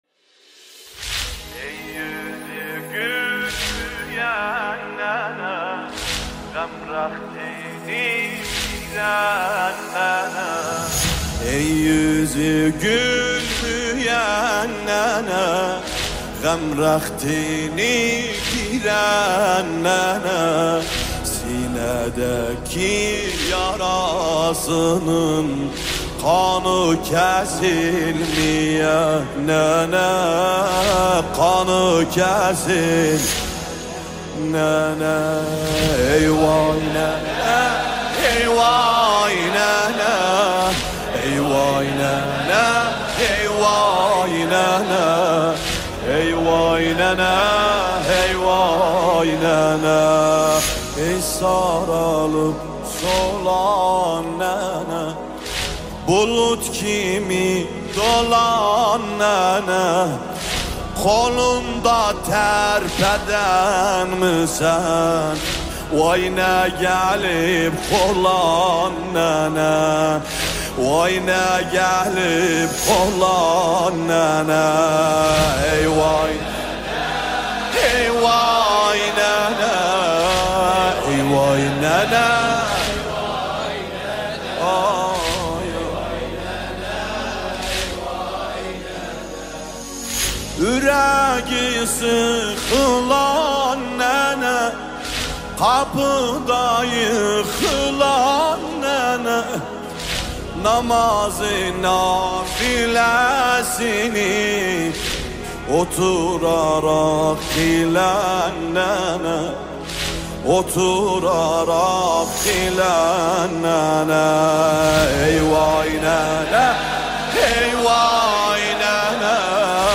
مداحی احساسی